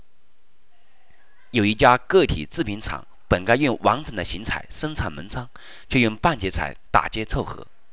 M: Male, F: Female
Vocoded GMM VAE